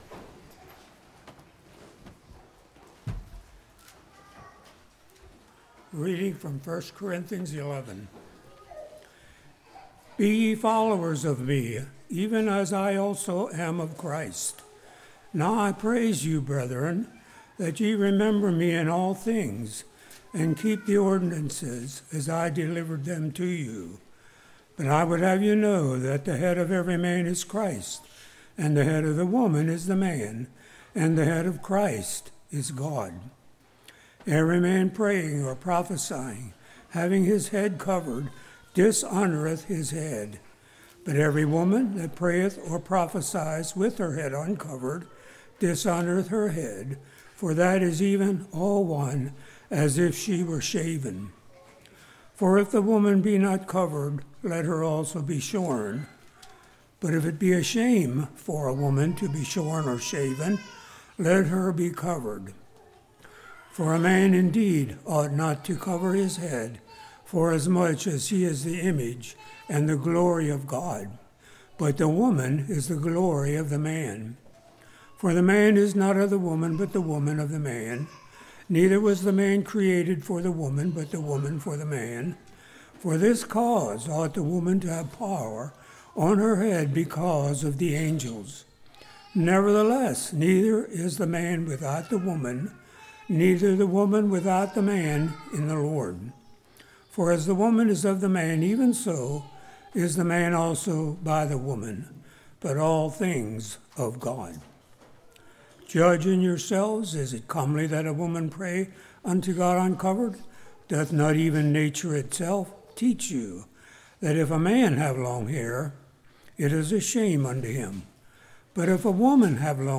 Series: Spring Lovefeast 2019
1 Corinthians 11:1-34 Service Type: Morning What Are We Examining For?